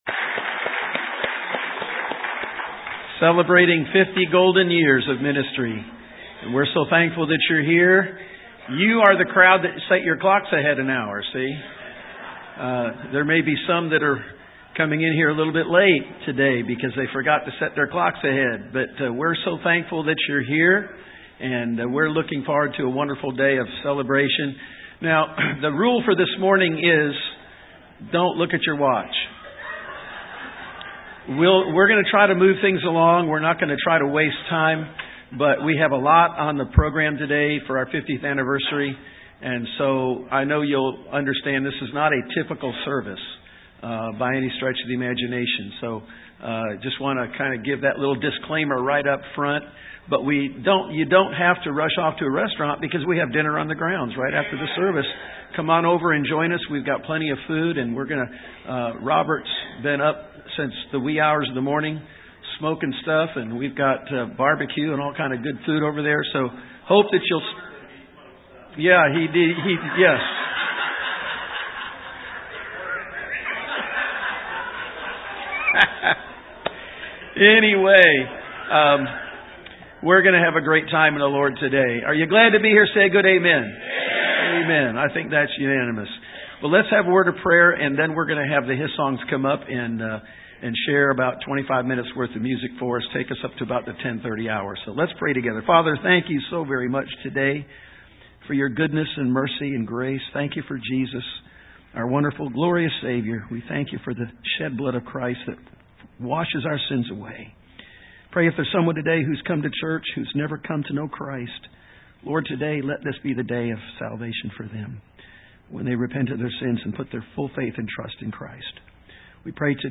50th Church Anniversary
Service Type: AM